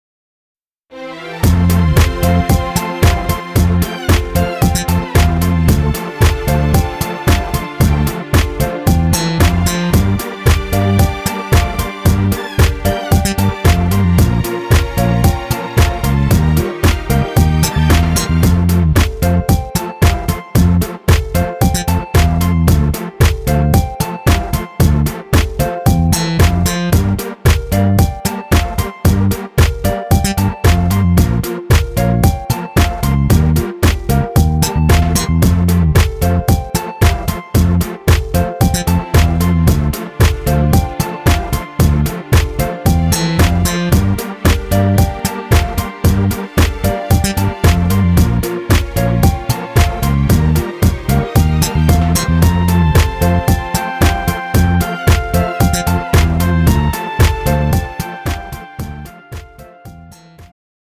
음정 여자키
장르 축가 구분 Pro MR